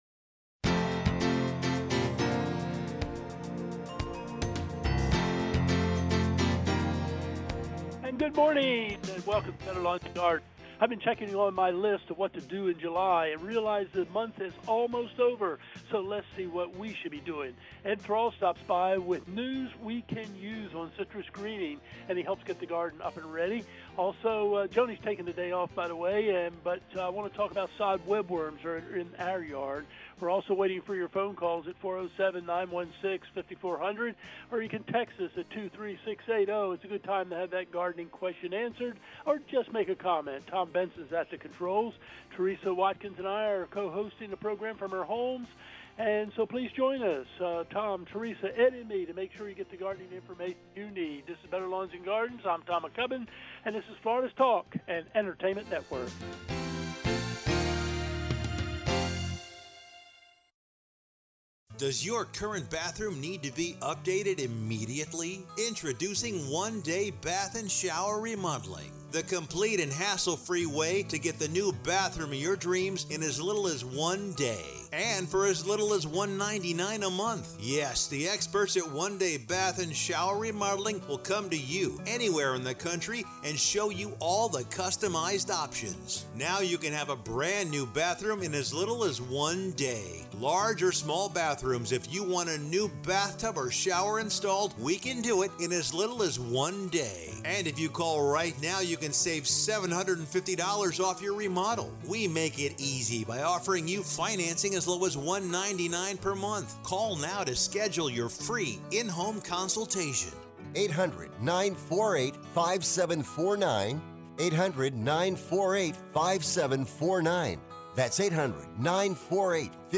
Gardening and text questions include spider plants as groundcover, North Florida landscape, sod webworm moths, caller says Summit mosquito dunks worked, and more.